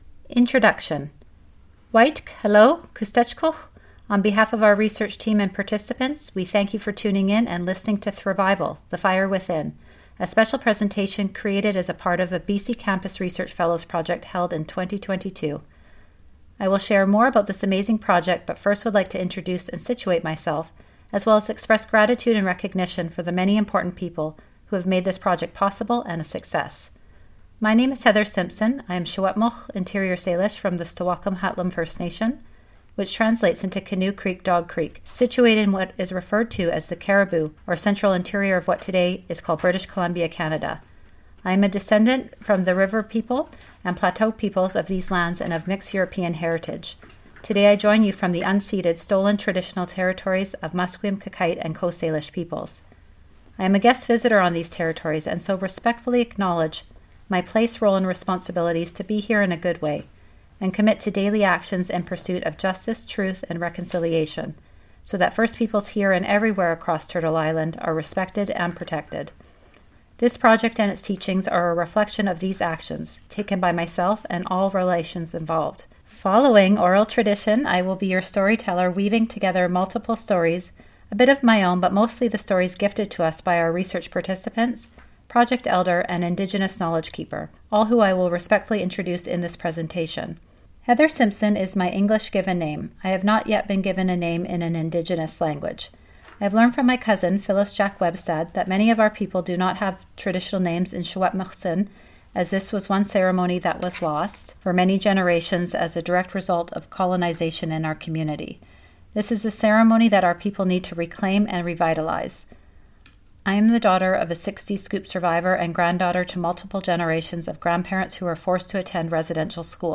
This is the oral recording of the report entitled Thrivival: the fire within. This research project provides a voice and leadership opportunity for Indigenous students with autism through participatory action research.